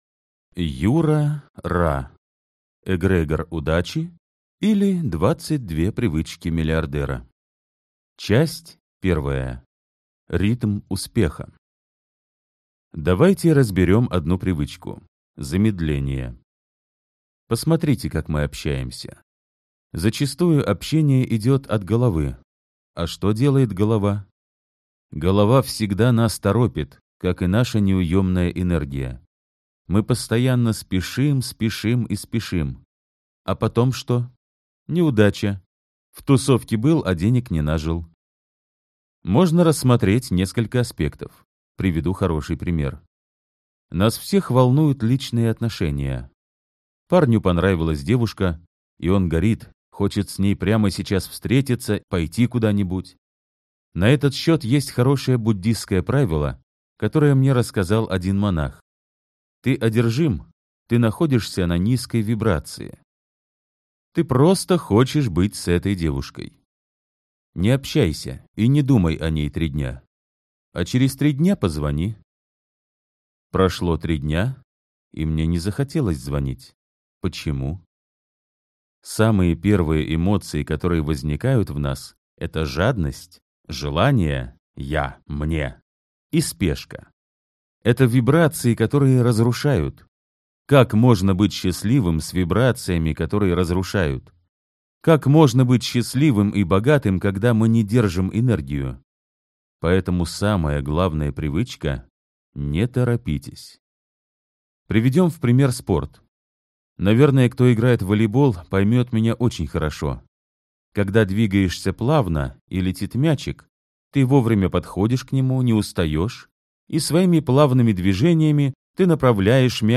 Аудиокнига Эгрегор удачи, или 22 привычки миллиардера | Библиотека аудиокниг